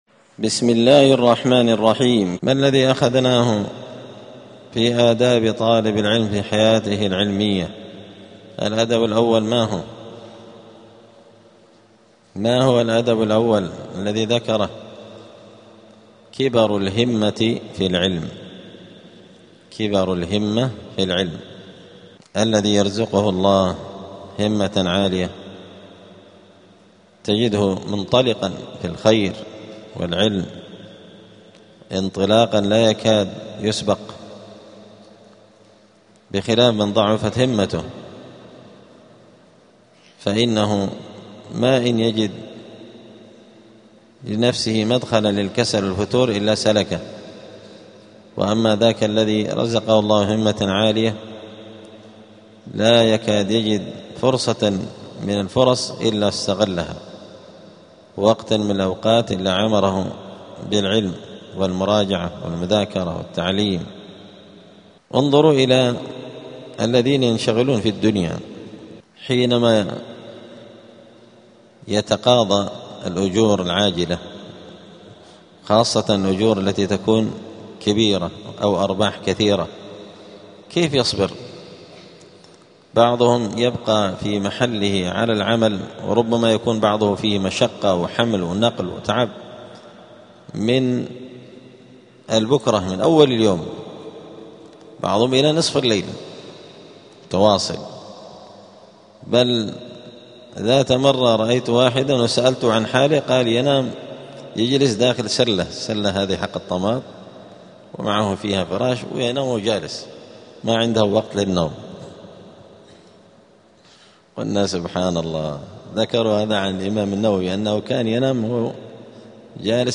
الخميس 8 جمادى الأولى 1447 هــــ | الدروس، حلية طالب العلم، دروس الآداب | شارك بتعليقك | 6 المشاهدات
دار الحديث السلفية بمسجد الفرقان قشن المهرة اليمن